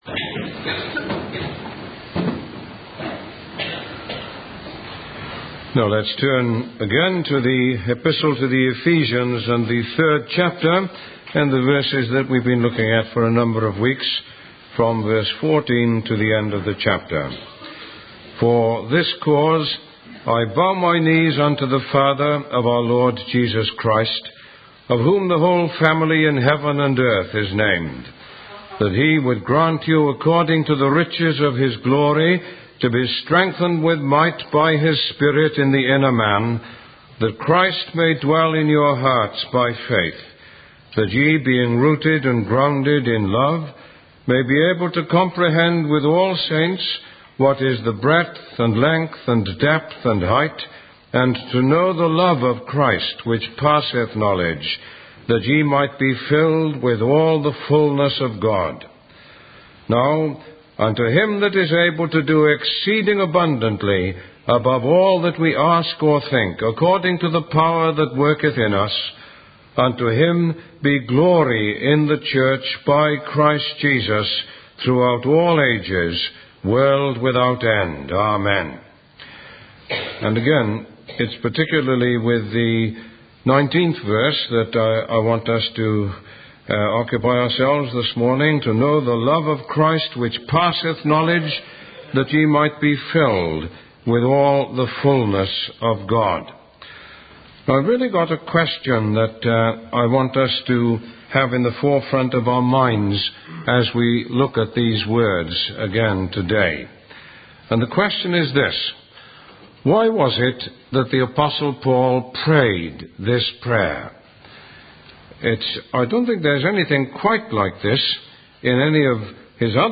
Sermon 6.mp3